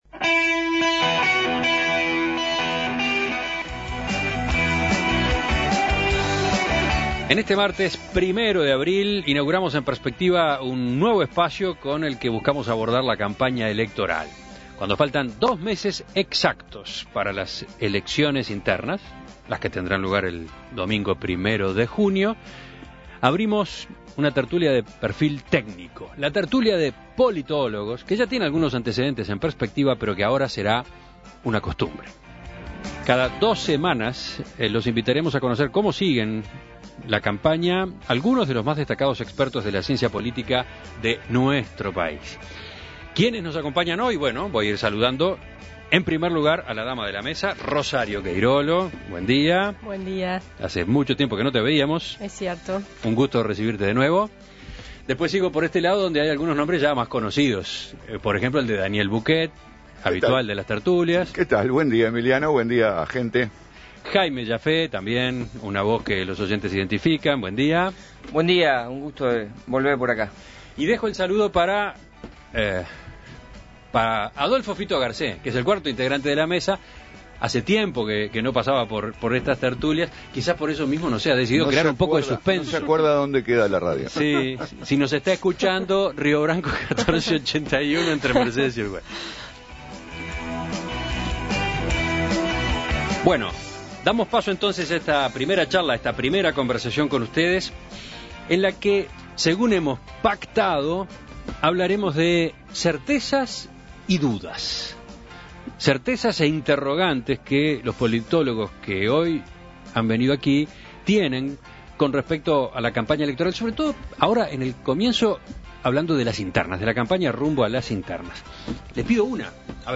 Tertulia de politólogos: Esta mañana les proponemos comenzar con las hipótesis y las interrogantes que los politólogos que nos acompañan en la mesa de hoy pueden pasar en limpio en este comienzo de campaña